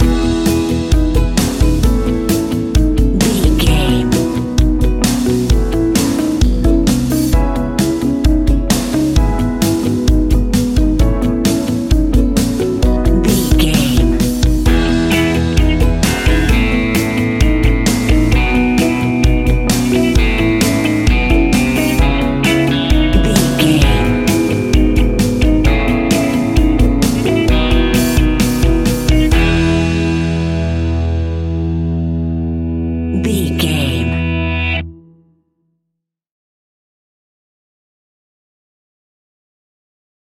Uplifting
Ionian/Major
pop rock
indie pop
fun
energetic
guitars
bass
drums
piano
organ